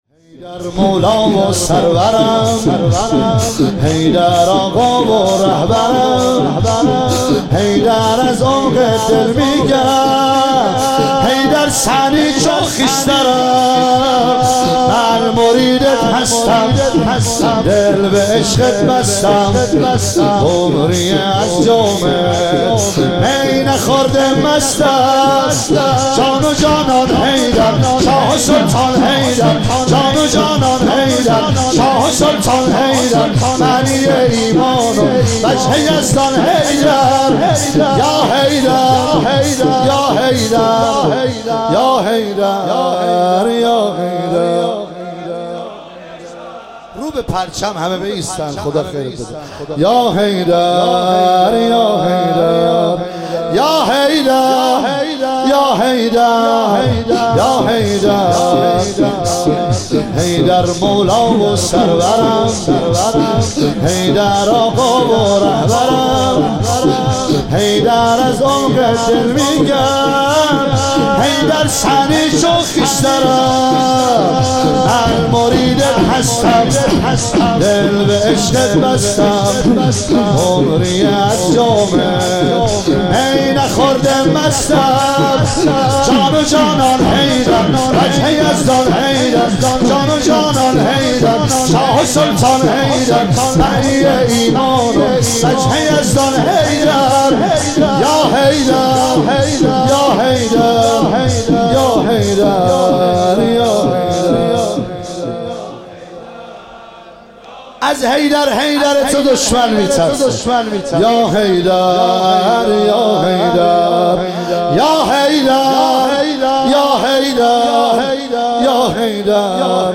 شب هشتم محرم 1398 – ساری
شور حیدر مولا و سرورم